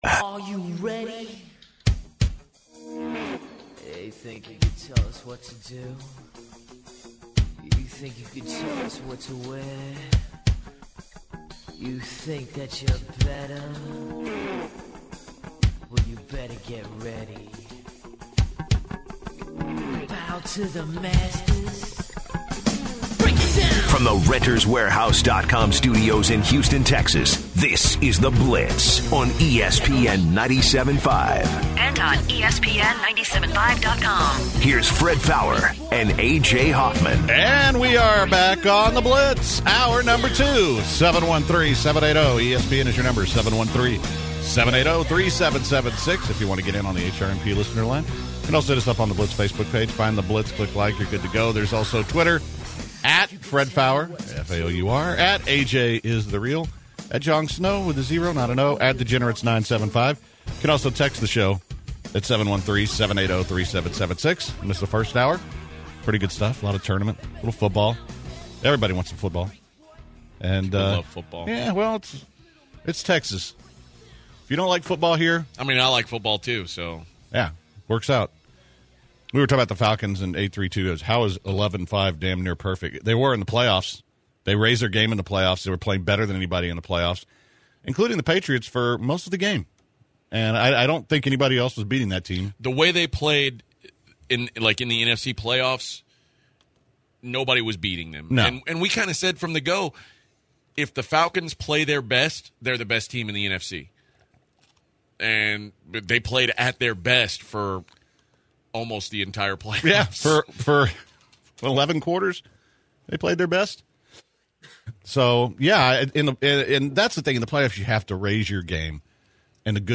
In the second hour of the show the guys talked NFL Free Agency, the Texans QB situation, and of course Tony Romo. The guys took a few called and ended the hour with the 'Gem of the Day'.